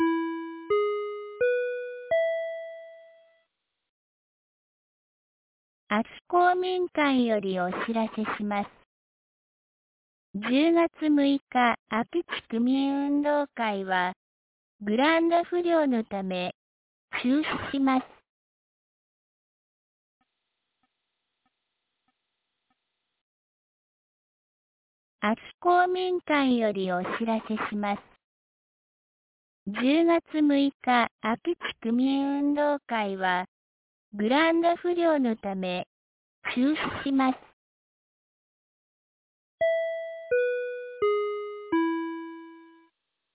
2024年10月05日 18時00分に、安芸市より安芸へ放送がありました。